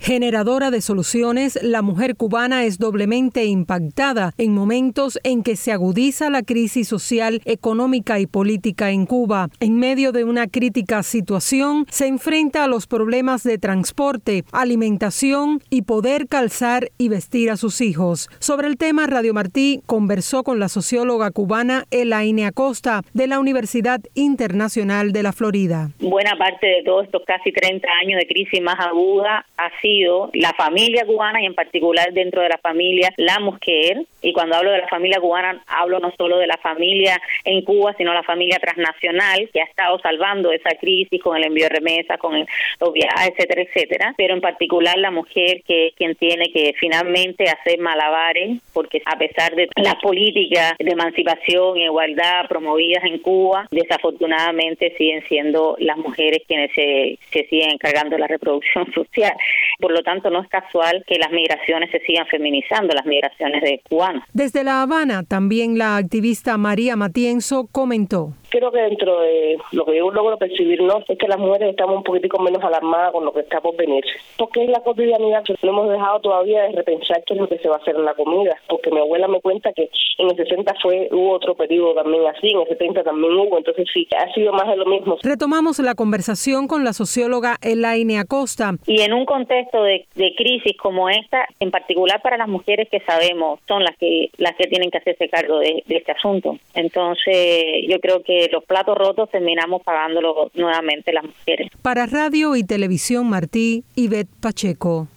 reporte con opiniones sobre el tema